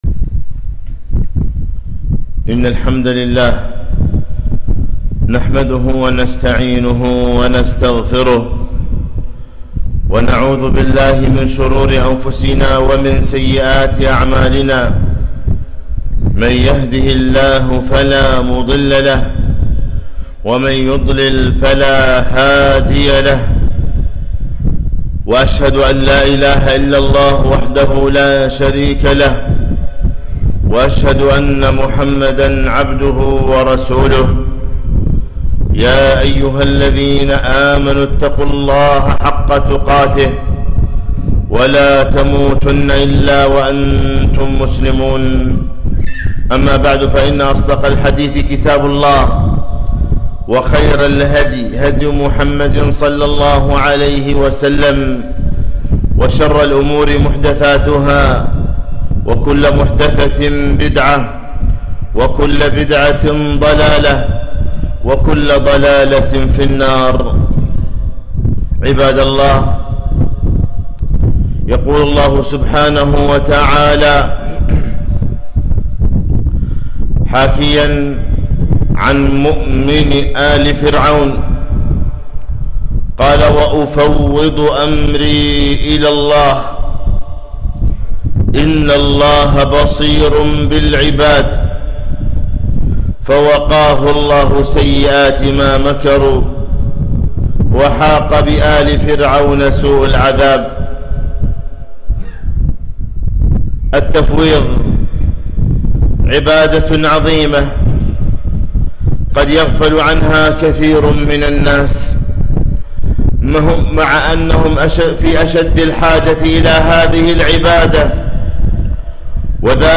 خطبة - وأفوض أمري إلى الله 2-3-1443